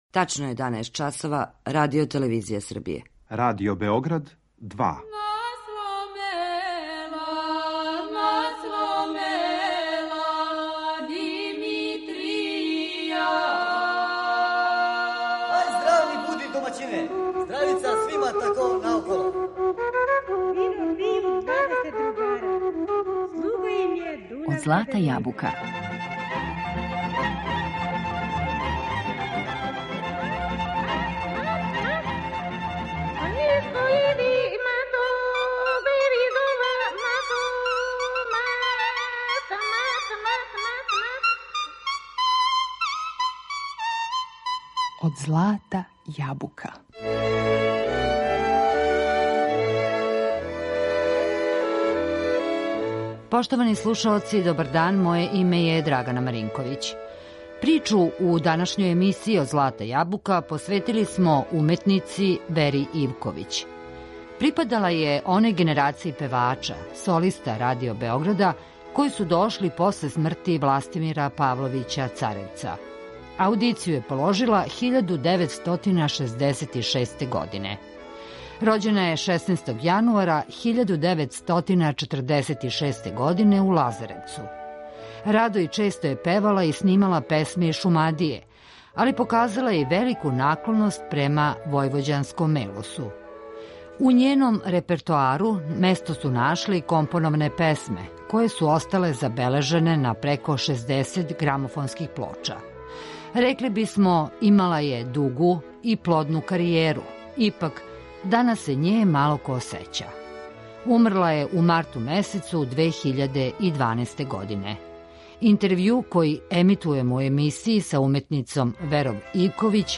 Радо и често је снимала песме из Шумадије, али је показала и велику наклоност према војвођанском мелосу.